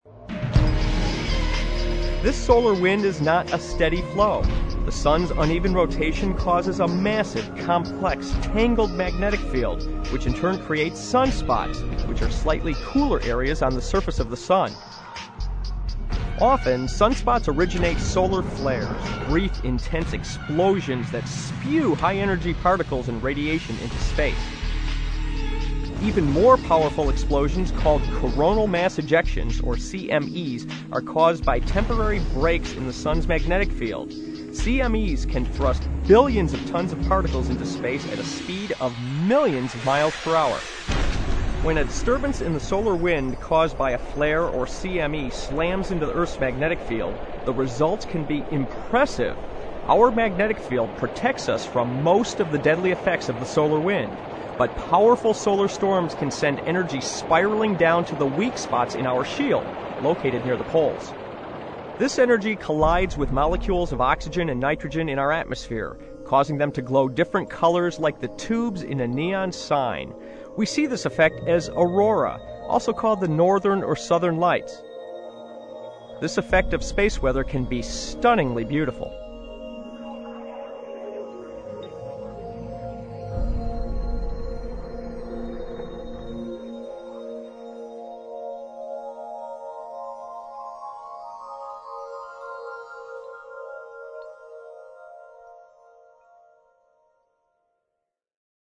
available in 5.1 surround sound or stereo